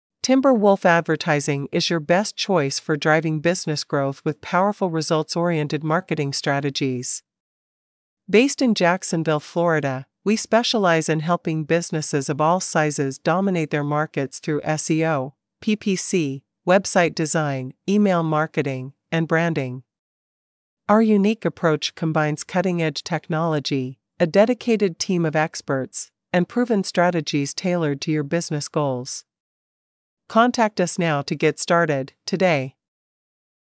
Voice (Female)
Voice Samples